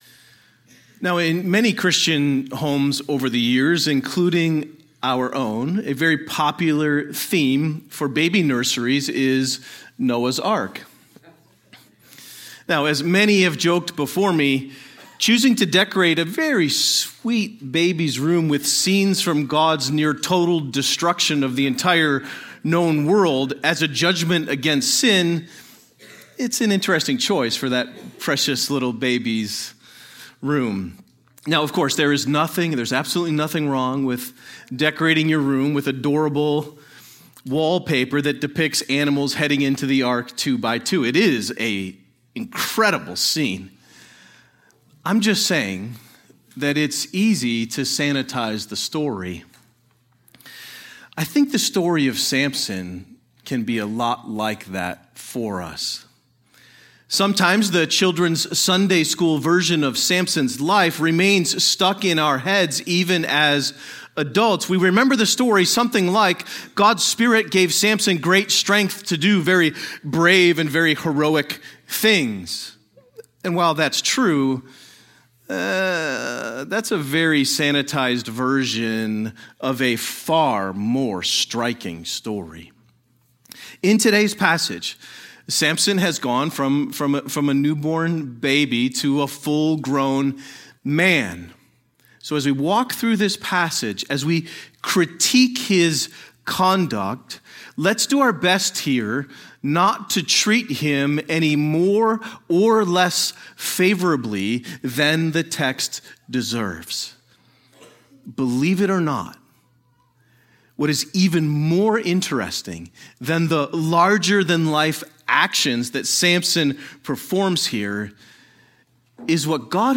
A sermon on Judges 14